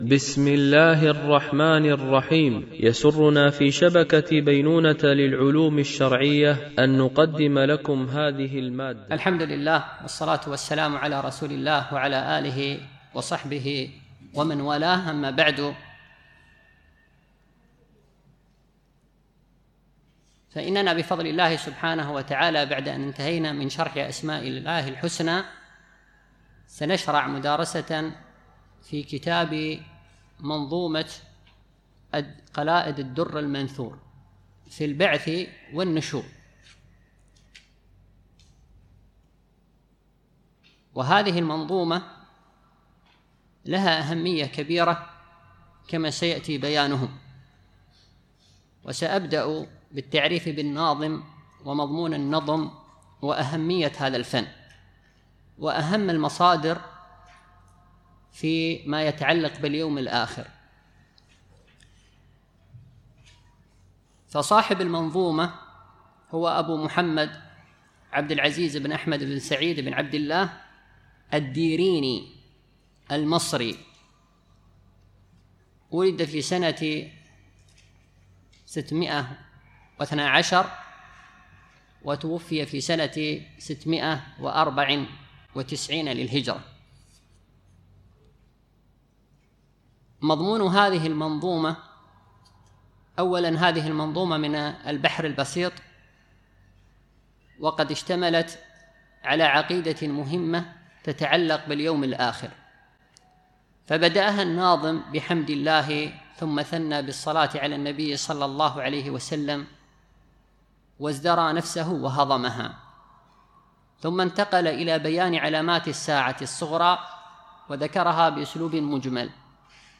شرح منظومة قلادة الدر المنثور في ذكر البعث والنشور ـ الدرس 01 ( المقدمة، البيت ١ - ٥)
MP3 Mono 44kHz 96Kbps (VBR)